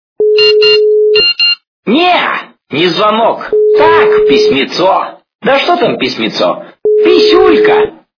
- звуки для СМС
При прослушивании Звонок для СМС - Не-а, не звонок, так, письмо качество понижено и присутствуют гудки.